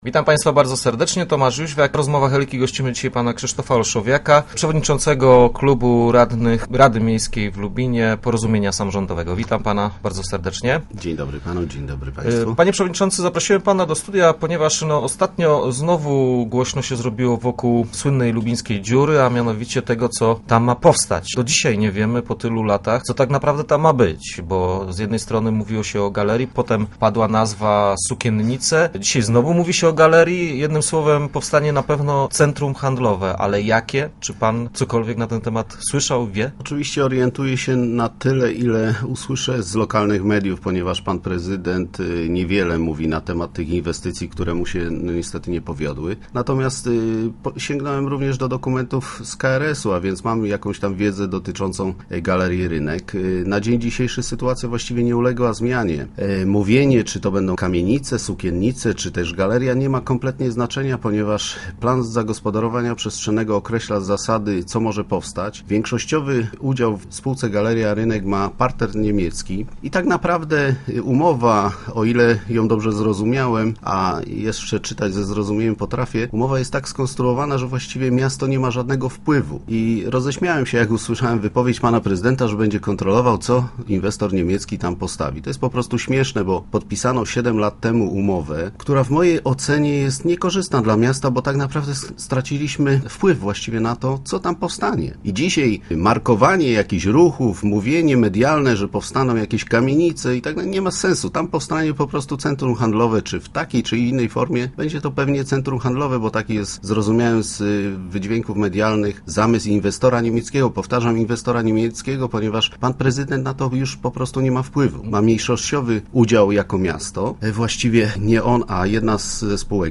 Start arrow Rozmowy Elki arrow Olszowiak: Prezydent strzelił sobie samobója
Naszym gościem był Krzysztof Olszowiak, szef klubu radnych Porozumienia.